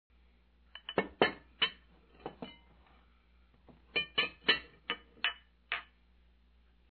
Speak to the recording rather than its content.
To create the composite auditory experience of Wickenden Pub, a simple digital audio recorder was used to record distinct sounds.